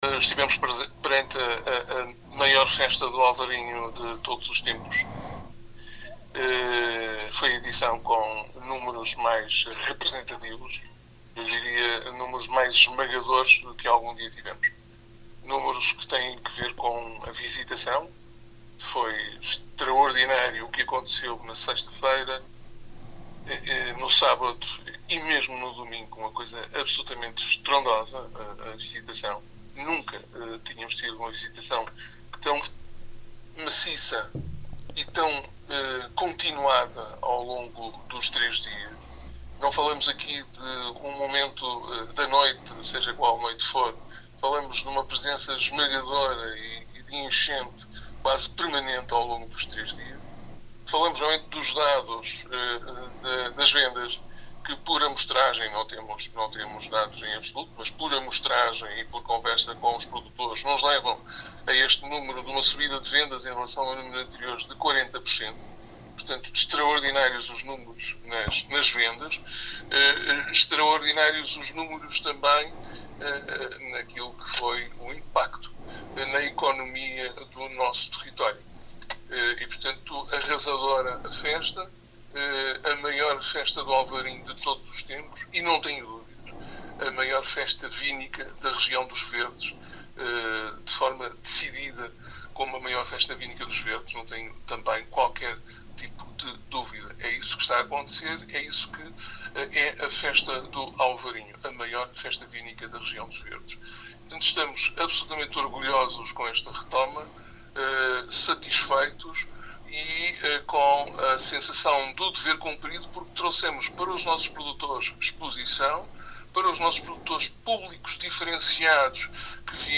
O balanço final foi feito esta quinta-feira à Rádio Vale do Minho pelo presidente da Câmara de Melgaço, Manoel Batista, no dia quem que foram divulgados os números finais e oficiais do certame que regressou no passado fim-de-semana, após dois anos de suspensão devido à COVID-19.
À Rádio Vale do Minho, Manoel Batista não escondeu a satisfação.